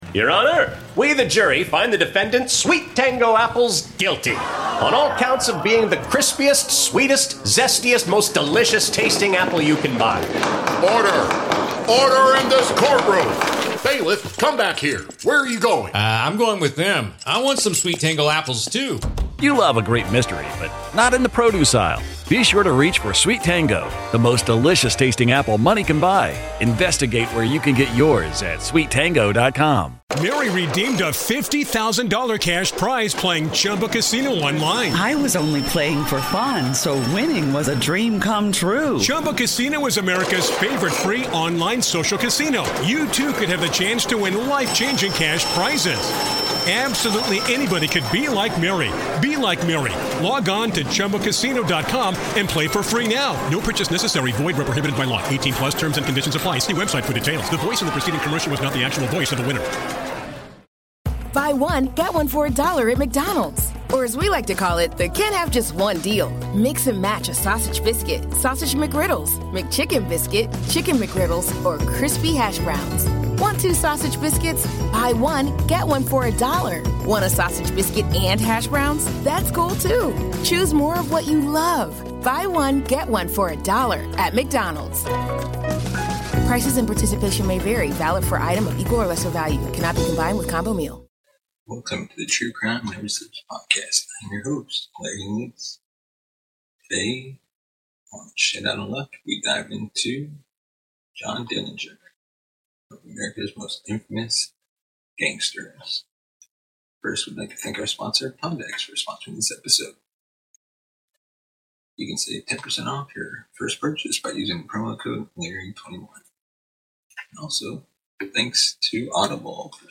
USB Lavalier Lapel Microphone